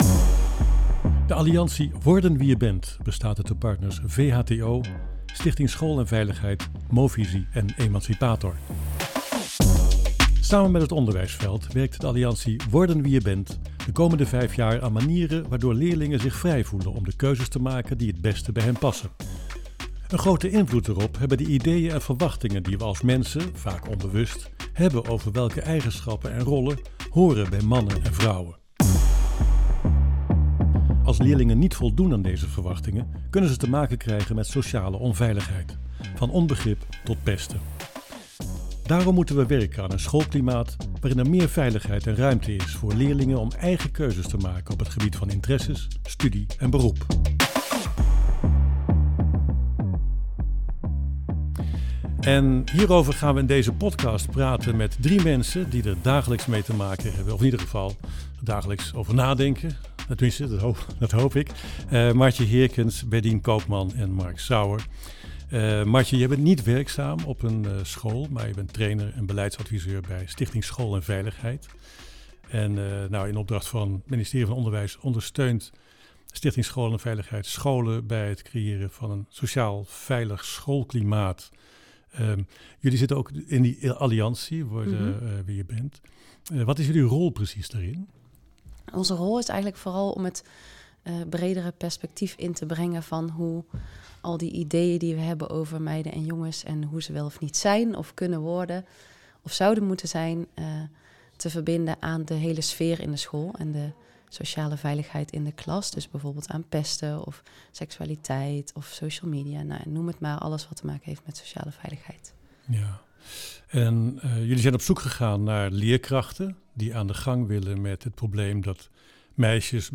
In de podcast ‘Worden Wie Je Bent in de praktijk’ delen leerkrachten hoe zij leerlingen helpen eigen keuzes te maken los van stereotypen.
De twee leraren vertellen waarom zij het belangrijk vinden om met deze thematiek bezig te zijn op school – en wat ze al concreet doen.
Deze podcast werd opgenomen na één alliantiejaar (jaar 1 van 5).